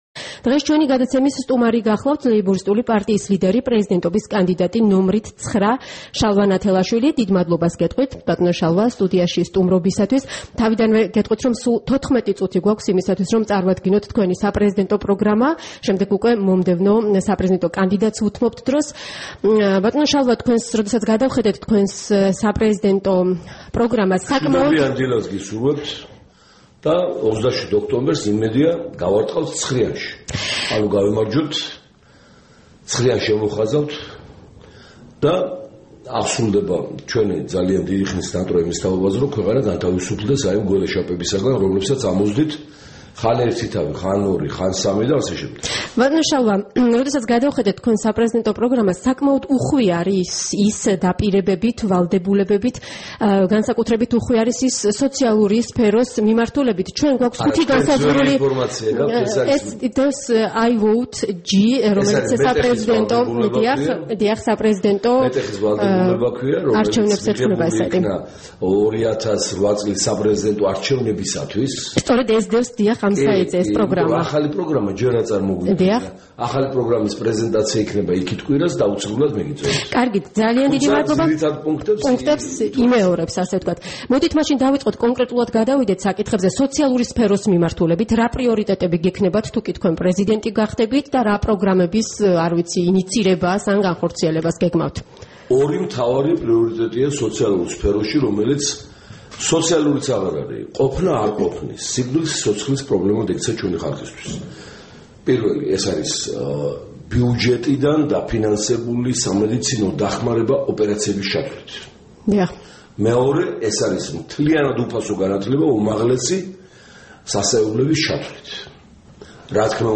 15 ოქტომბერს რადიო თავისუფლების დილის გადაცემის სტუმარი იყო შალვა ნათელაშვილი, „ლეიბორისტული პარტიის“ პრეზიდენტობის კანდიდატი, რიგითი ნომრით - 9.